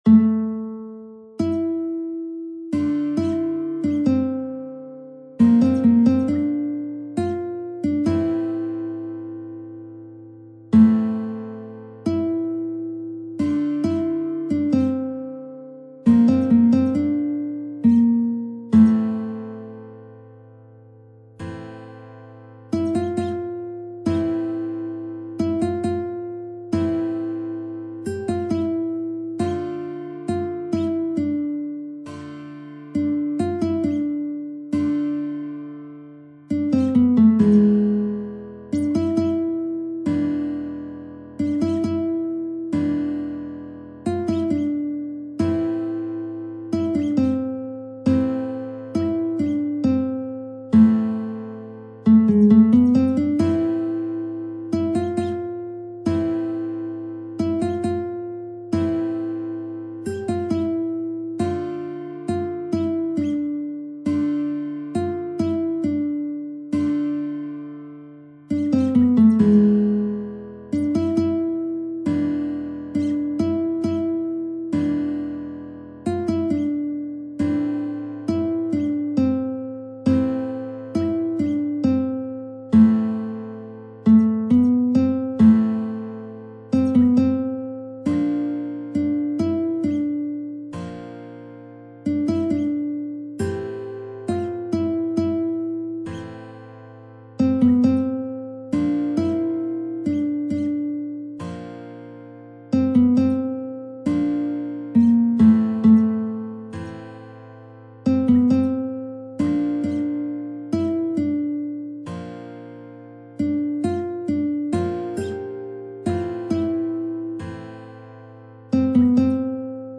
نت ملودی به همراه تبلچر و آکورد و شعر ترانه